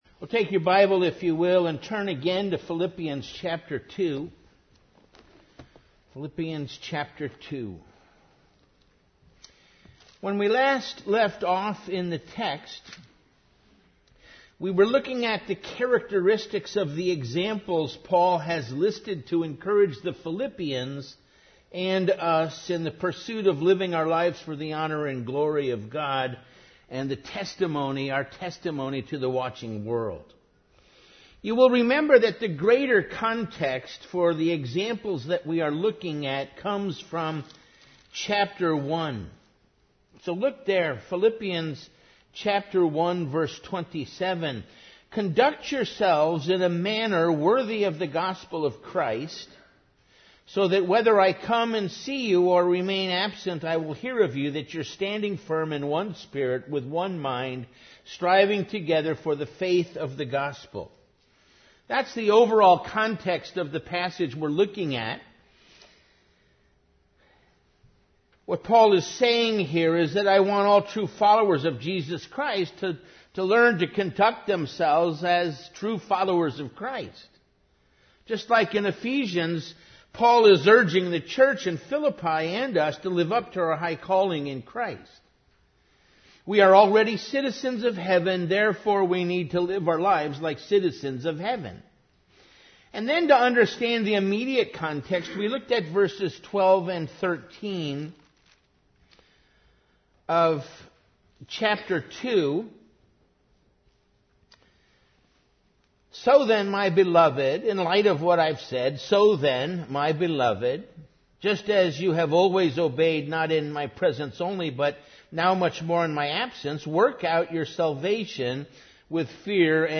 Series: Evening Worship